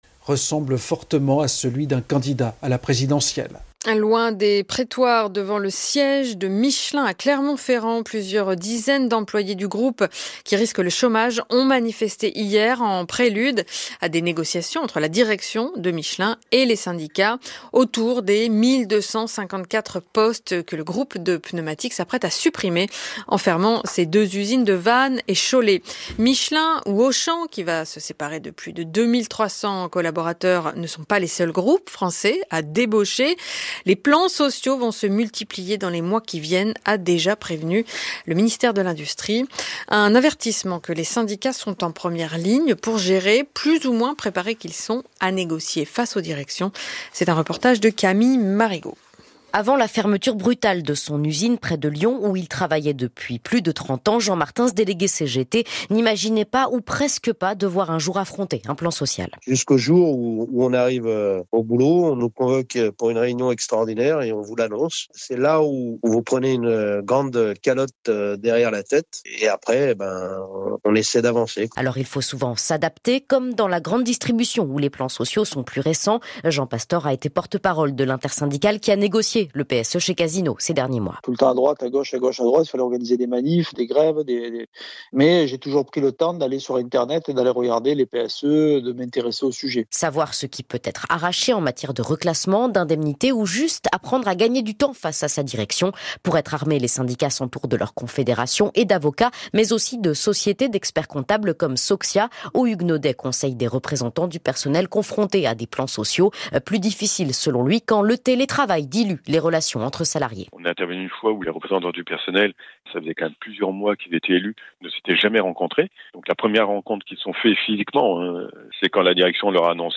Interview · France Culture du 14 novembre 2024.
🎧 Extrait de l’interview France Culture sur les PSE - Michelin, Auchan -